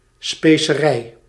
Ääntäminen
IPA : /spaɪs/